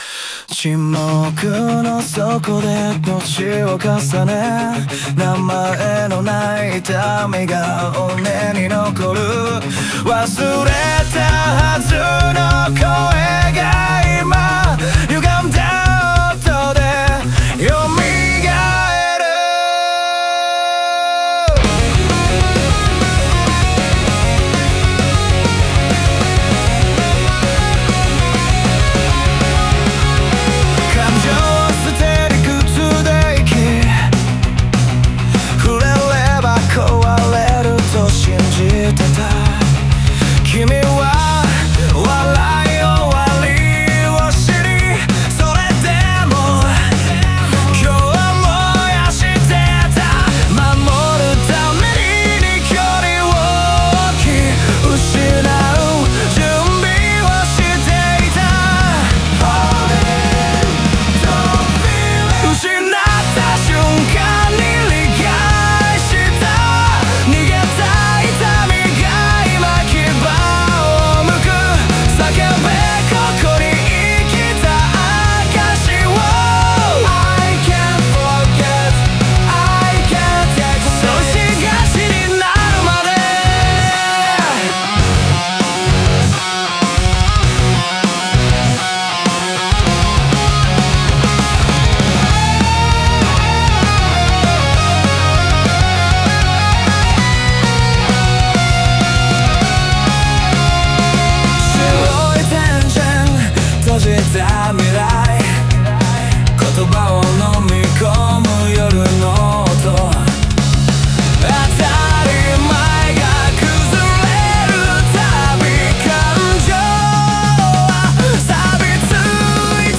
その内面の葛藤を、重低音のリフや加速するリズムに重ね、抑圧から爆発、そして受容へと段階的に描写している。